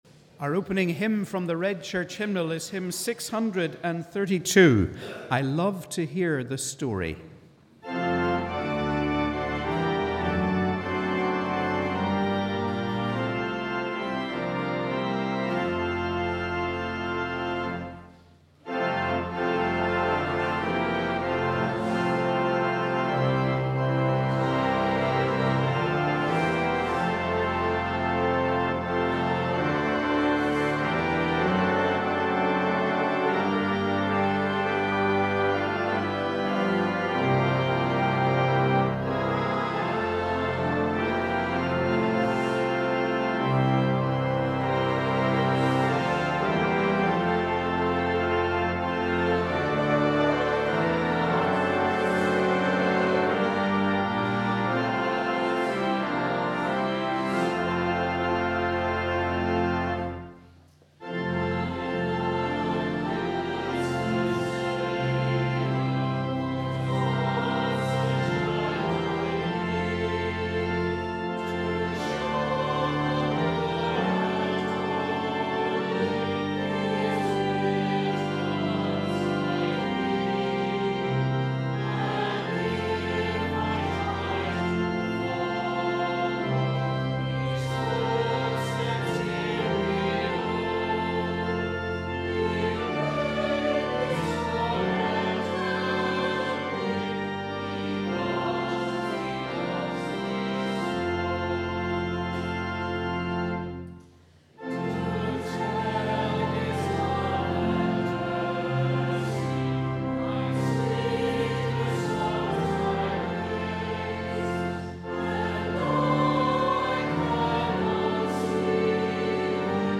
We warmly welcome you to our service of Holy Communion on the 3rd Sunday of Easter.